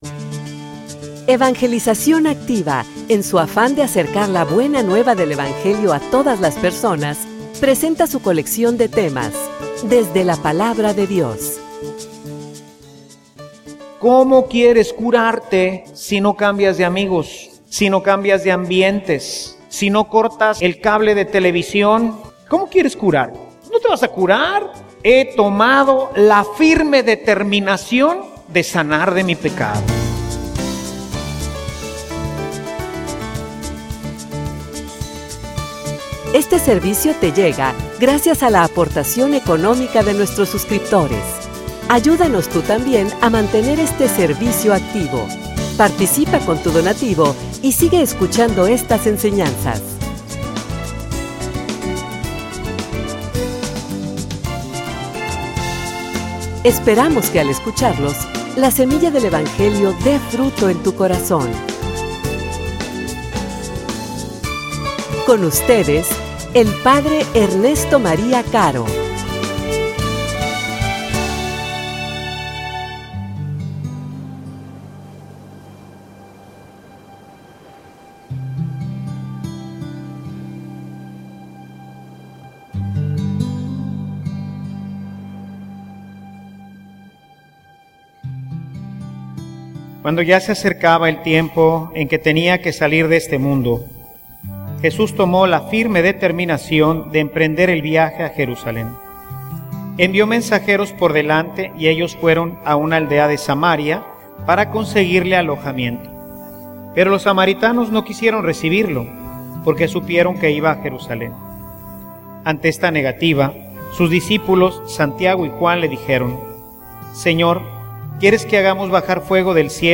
homilia_Termina_lo_que_inicies.mp3